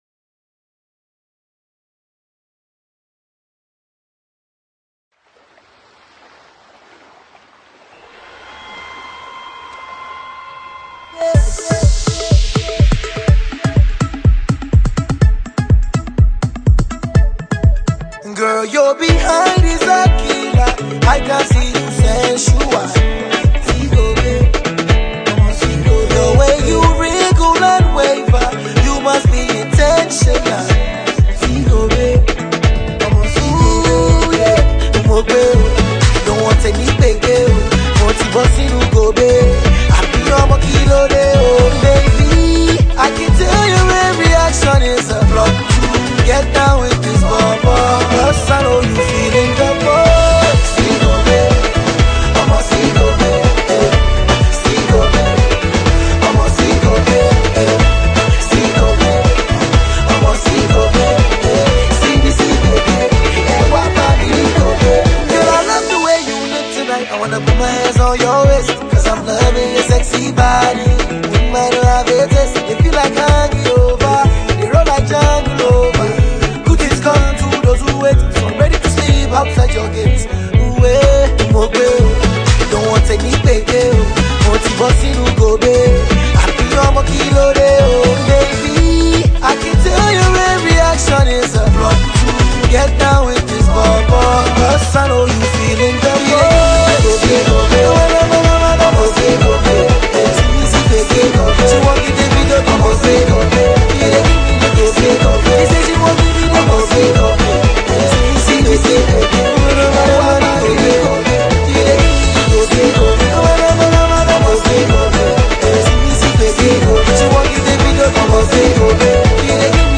he’s back with a funky new single